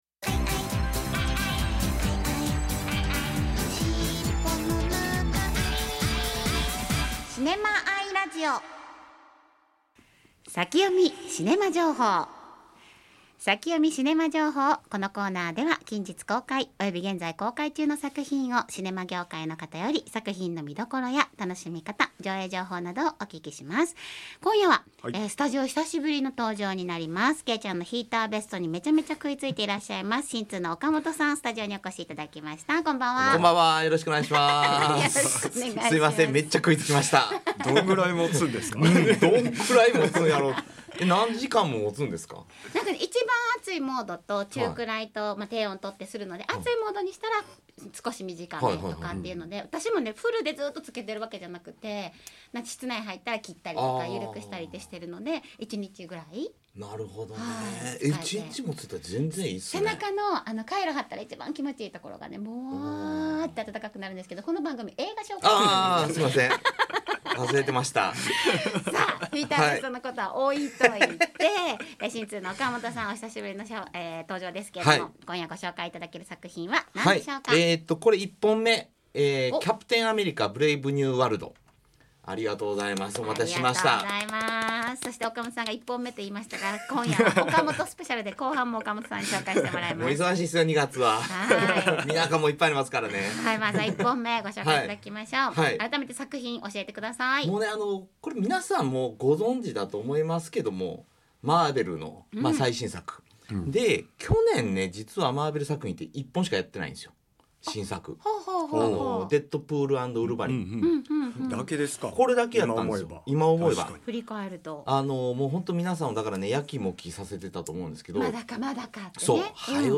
映画初主演の水川かたまりさんにスペシャルインタビュー！